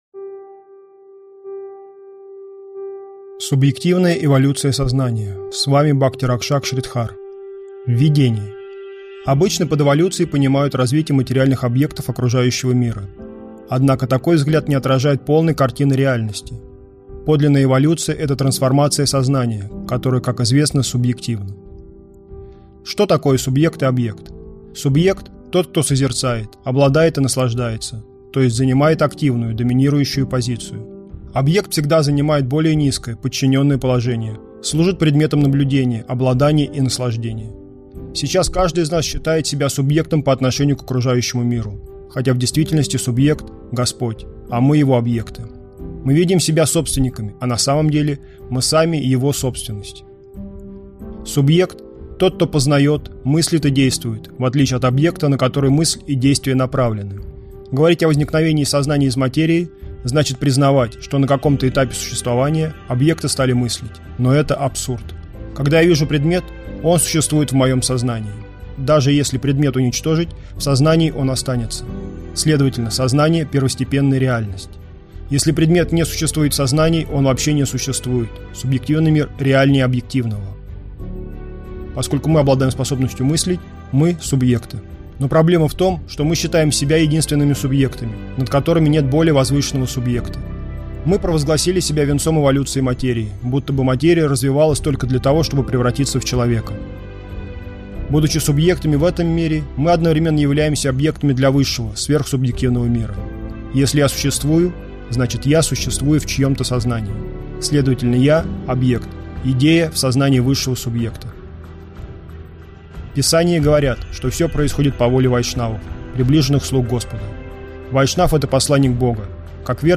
Аудиокнига Субъективная эволюция сознания | Библиотека аудиокниг